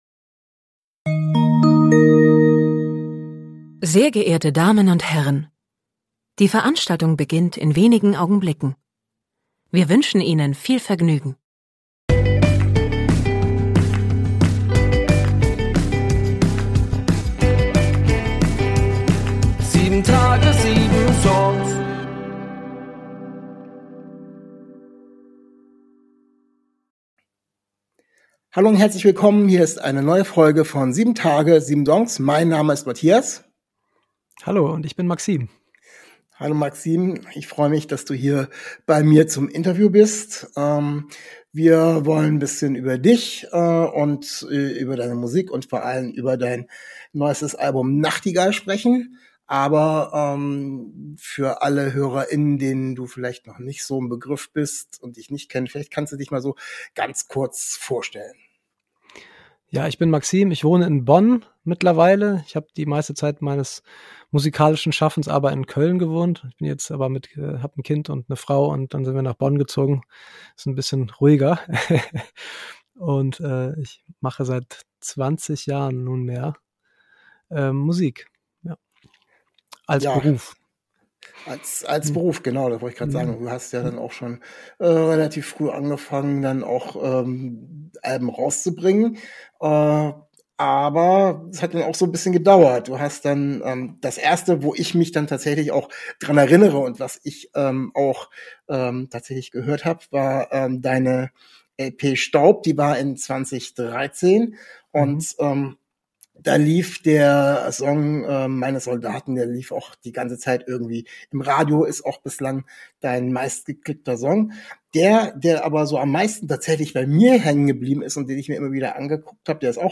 Zu Gast ist der musiker MAXIM, der über sein neues Album "Nachtigall" erzählt.